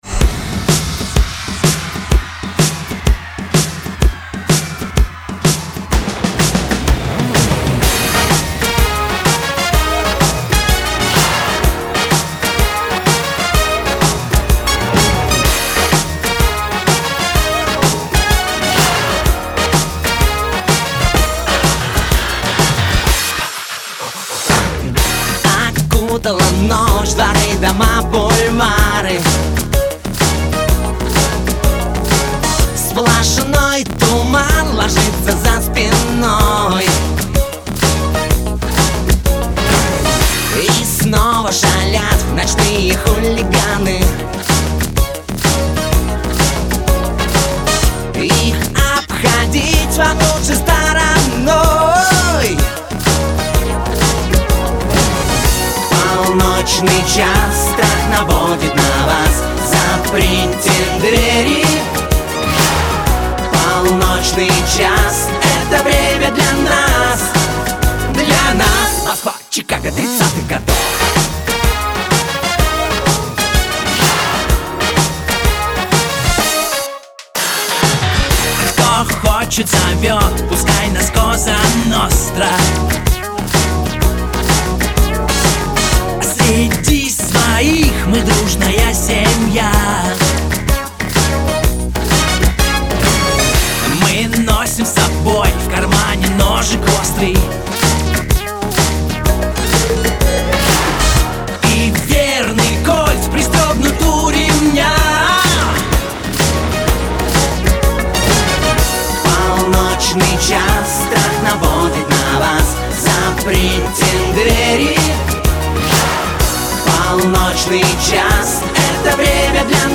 КОНЦОВКА ОБРУБЛЕННАЯ